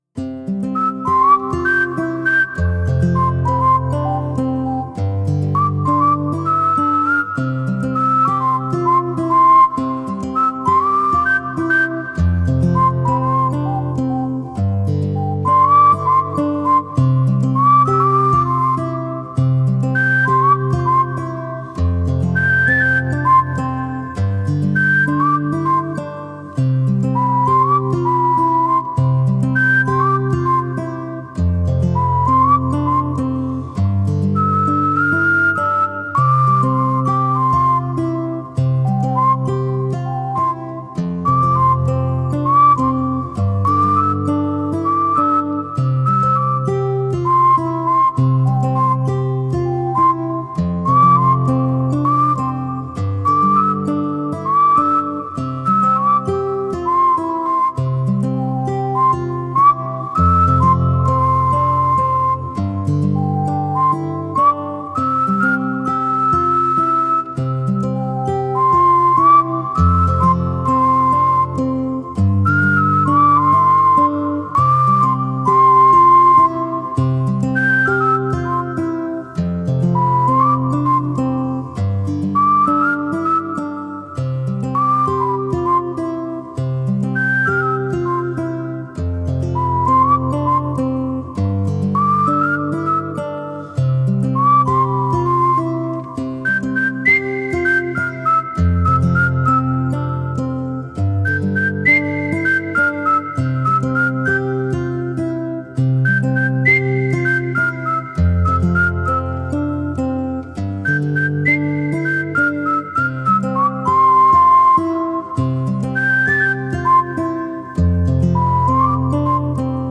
ギターと口笛の明るい曲です。【BPM100】